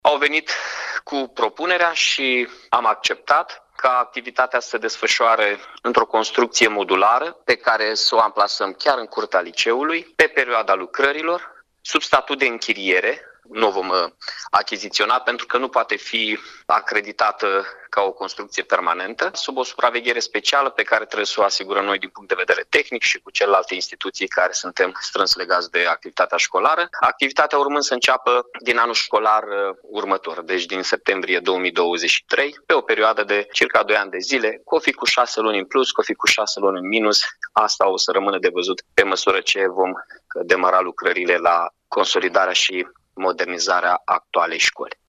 Lucrările vor dura cel puțin doi ani, a precizat Mihai Chirica: